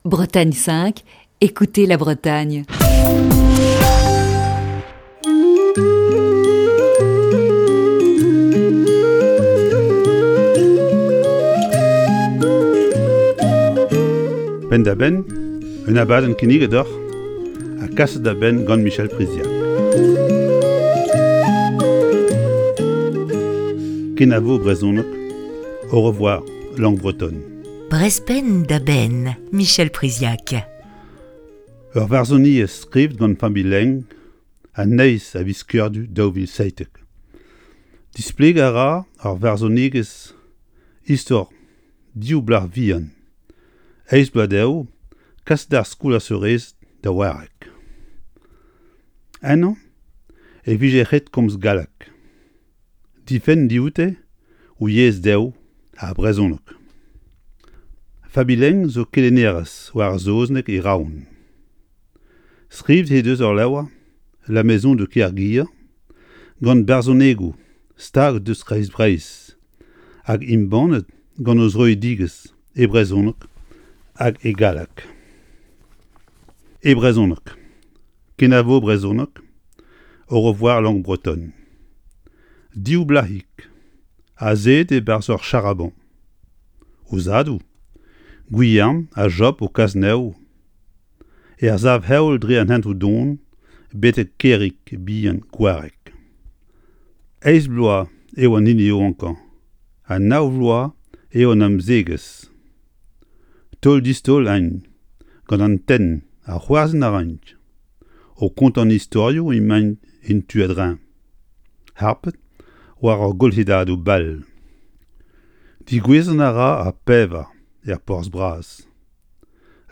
la lecture en breton